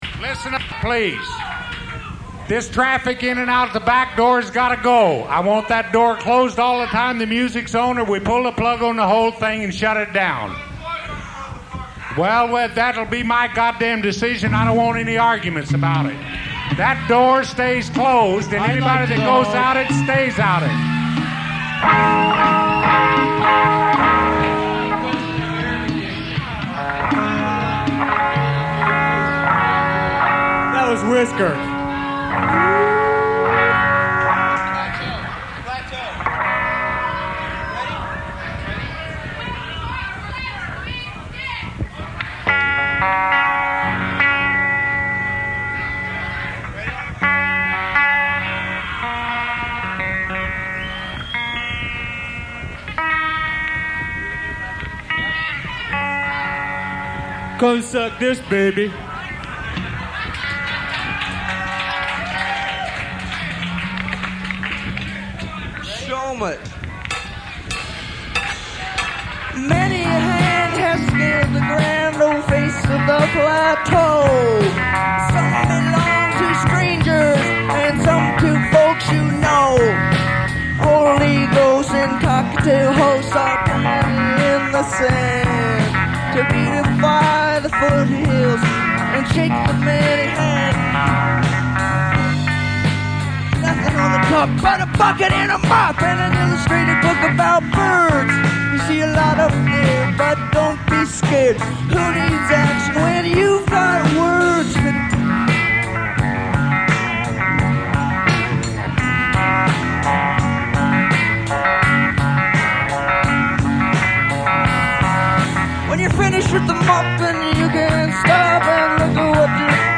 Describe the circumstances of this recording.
live Whiskers, Phoenix, Aug. 19, 1983